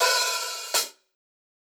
Closed Hats
HIHAT_DEATH.wav